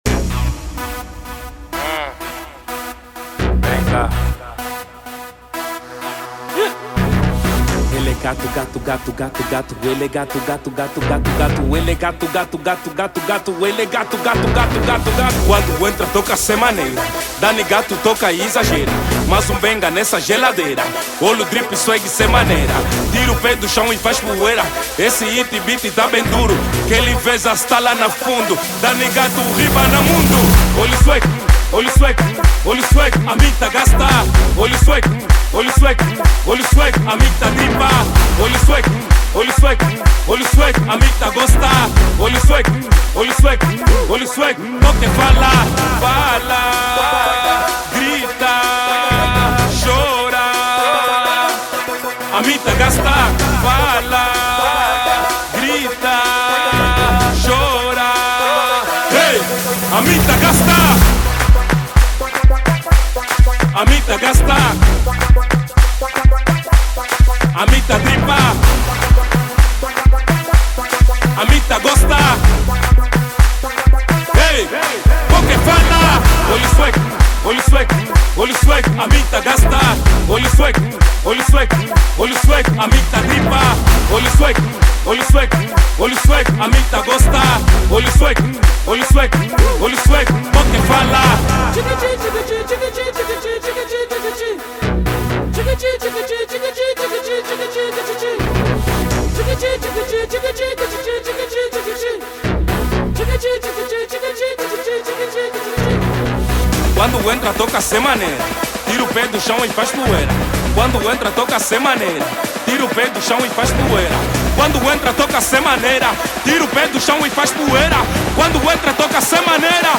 2024-02-16 16:59:44 Gênero: Axé Views